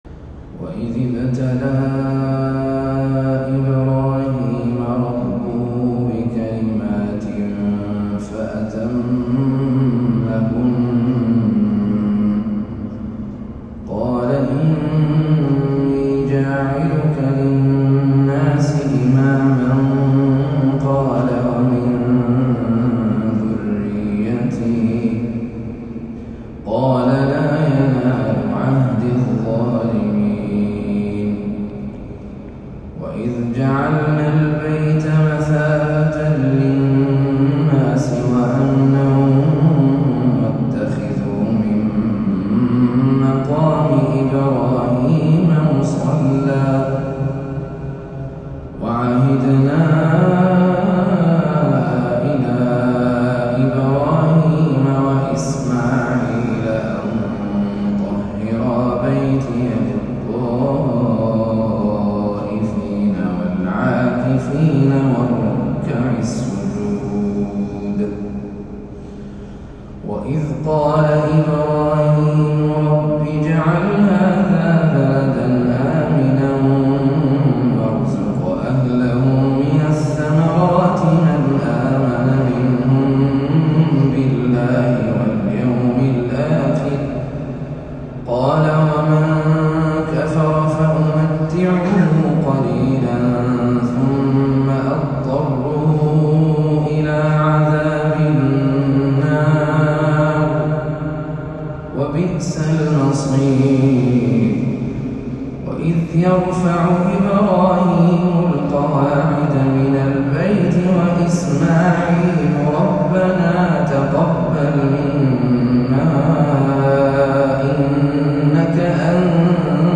تلاوة فجرية خاشعة
مسجد الخندق ، المدينة النبوية